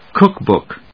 音節cóok・bòok 発音記号・読み方
/ˈkʊˌkbʊk(米国英語)/